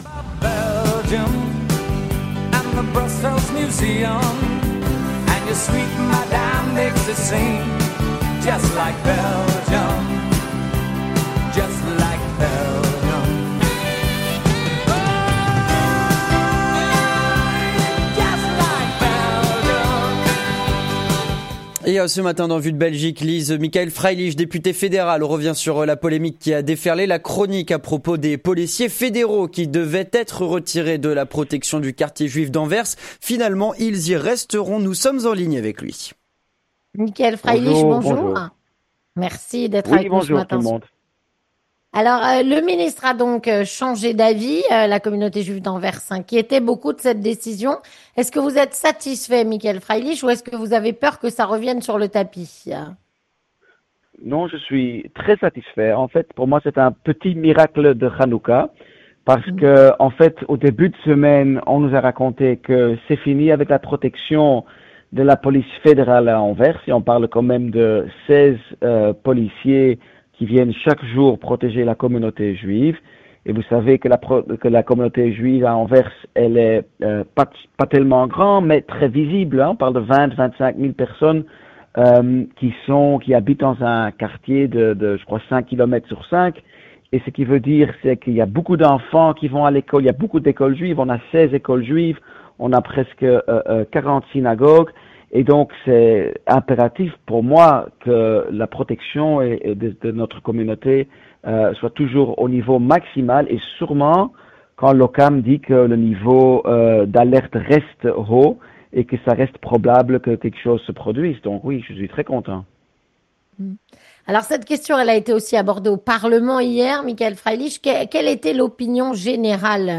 Avec Michael Freilich, député fédéral N-VA